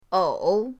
ou3.mp3